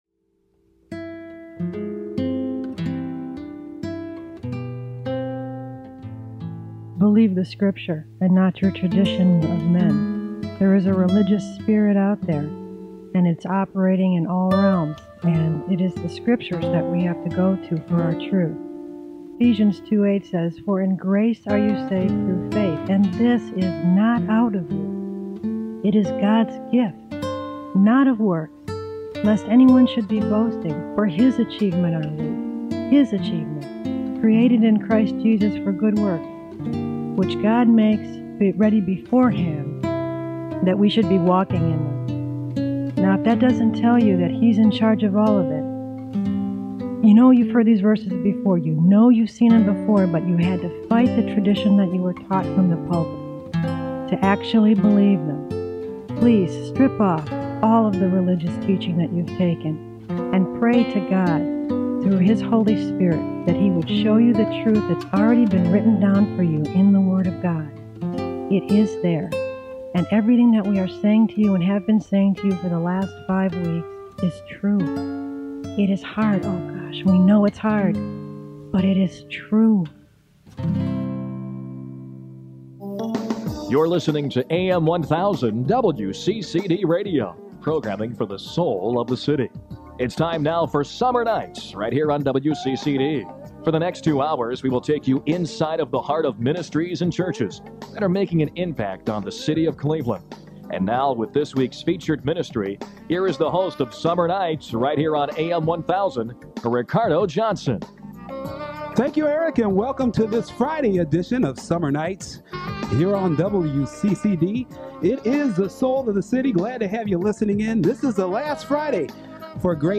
Grace Café was a live radio show broadcast from Cleveland, Ohio in the summer of 1999.